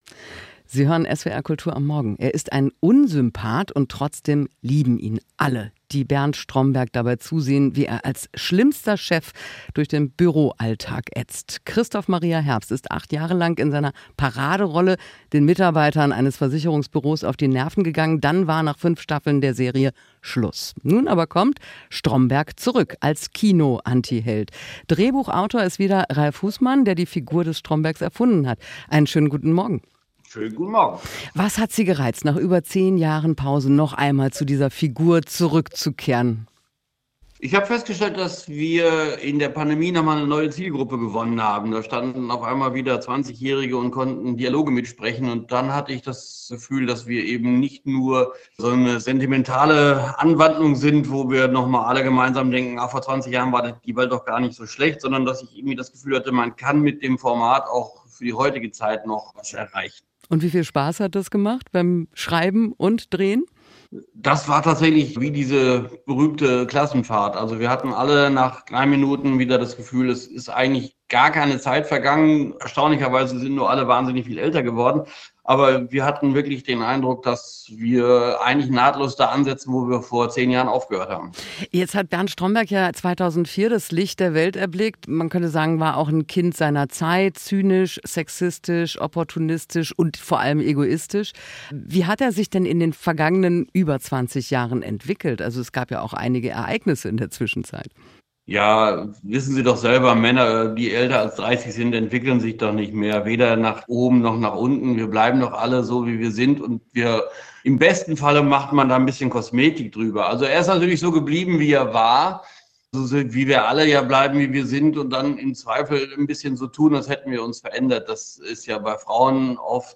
Gespräch
Interview mit